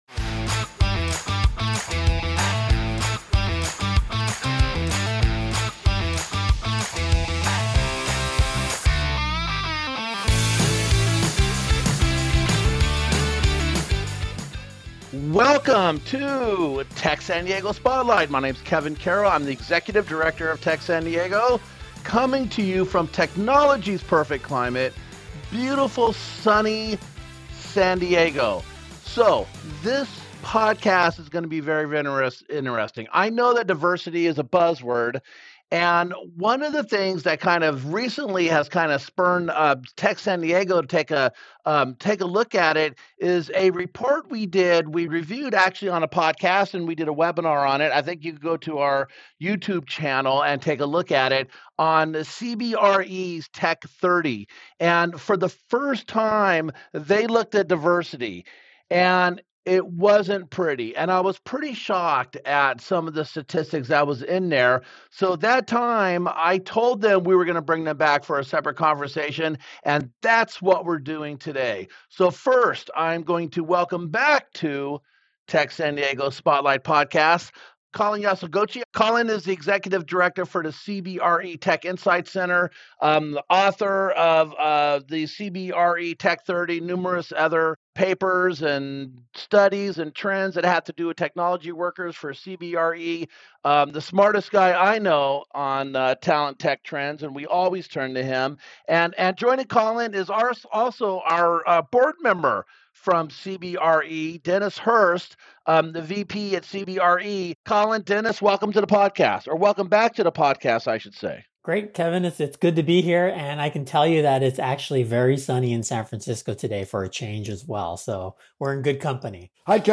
This episode of TSD Spotlight was recorded remotely and edited by Hypable